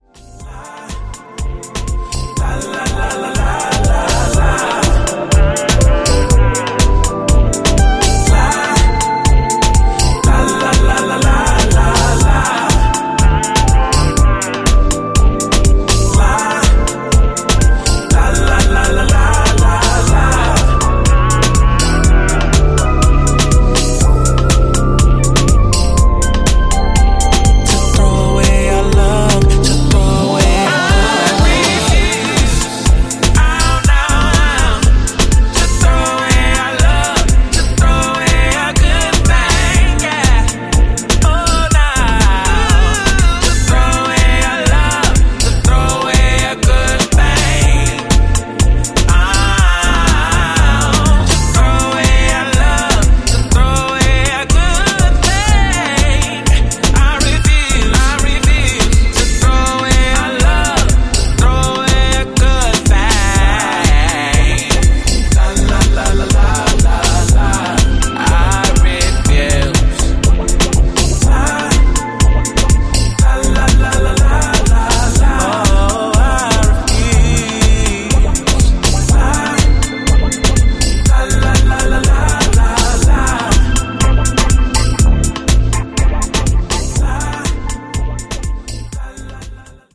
ジャンル(スタイル) SOULFUL HOUSE / DEEP HOUSE